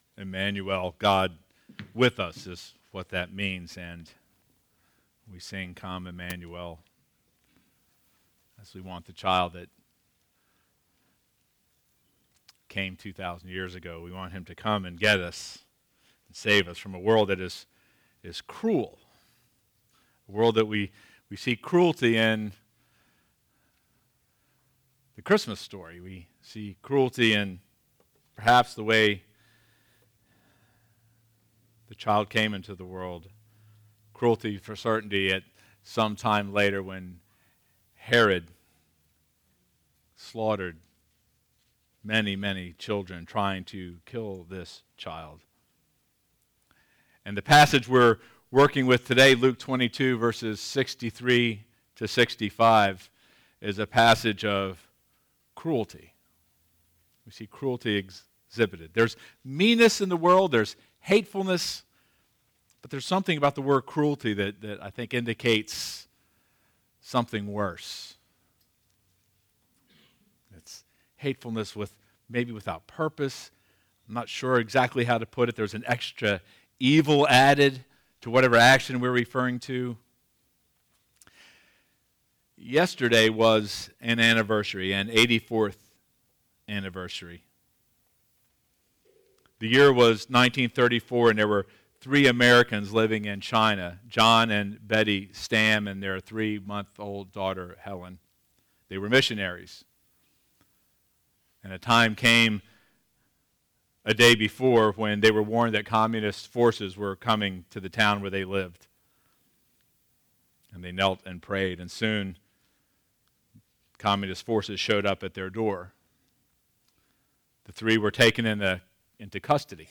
Sermon Notes Jesus resurrected w/ a real body seated at the right hand of God Stood up to help stephen.